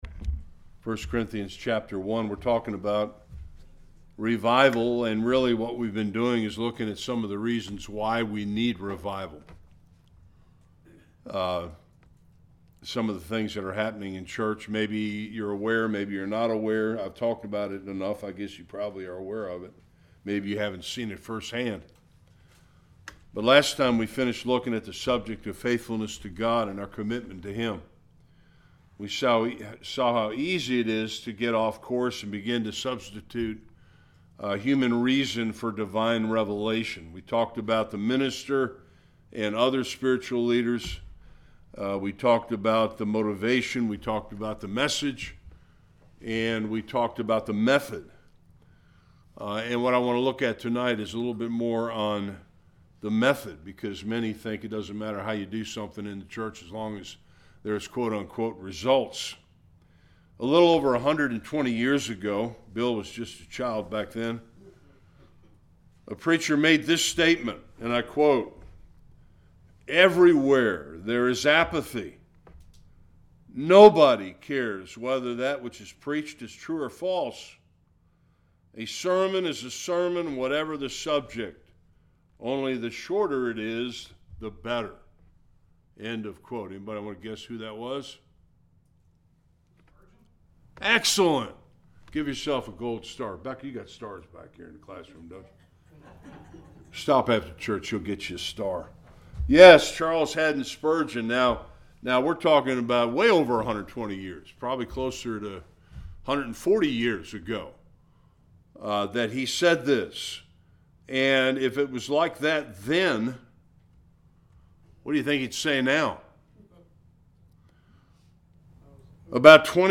Various Passages Service Type: Bible Study Does the LORD really care what methods we use to try and get people to attend our church services?